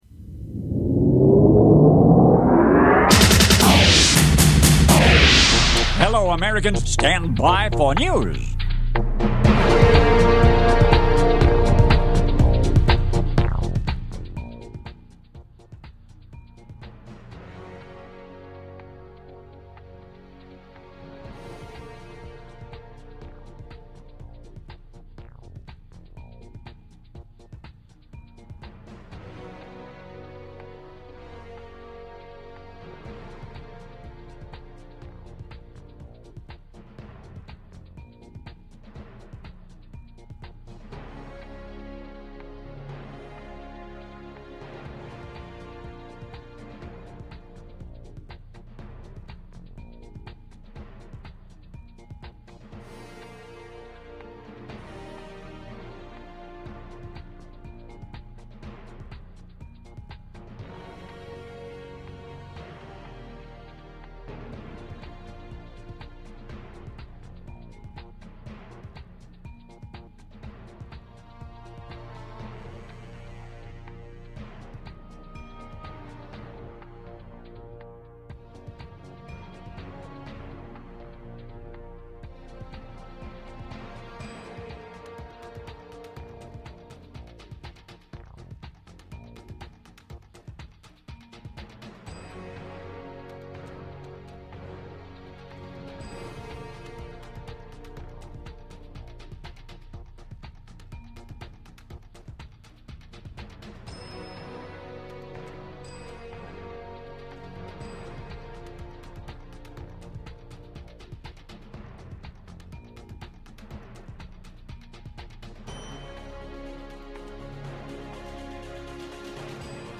News Segment
Category: Radio   Right: Personal